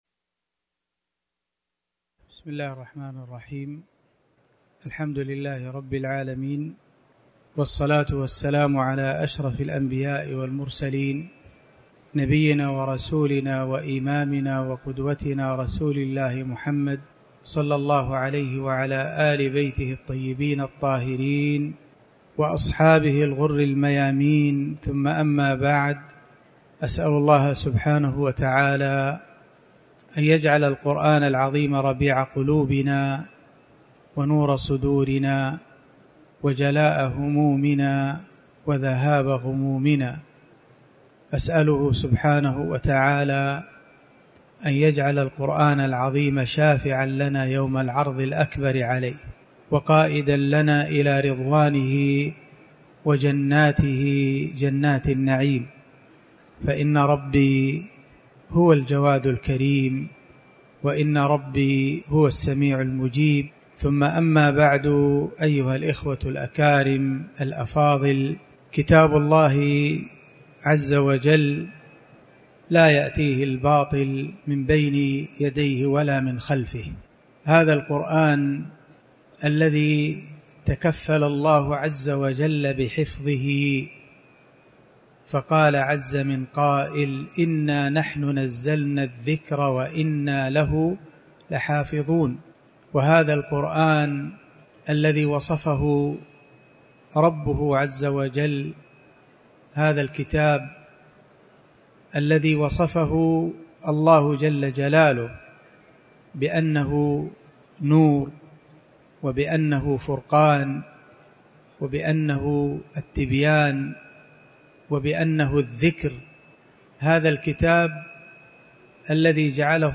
تاريخ النشر ٢٨ شوال ١٤٤٠ هـ المكان: المسجد الحرام الشيخ